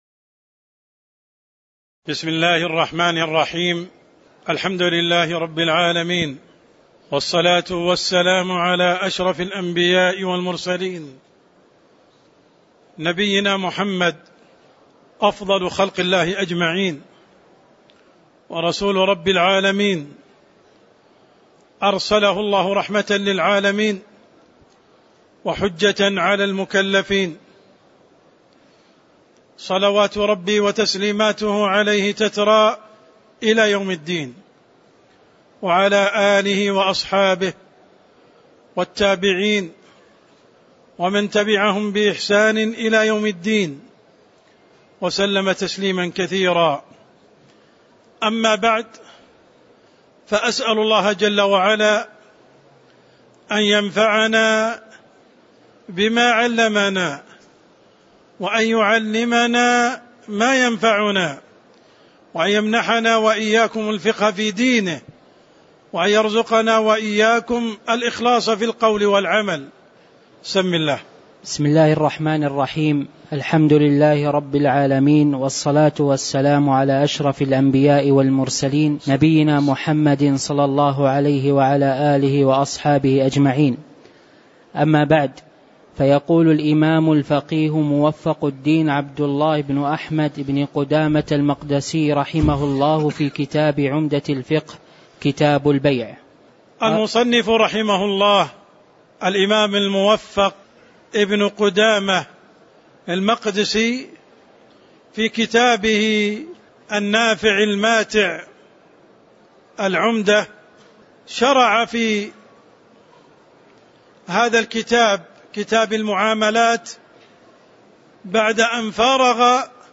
تاريخ النشر ٢١ صفر ١٤٤٠ هـ المكان: المسجد النبوي الشيخ: عبدالرحمن السند عبدالرحمن السند قوله: وقال تعالى وأحلّ الله لكم البيع وحرّم الربا (03)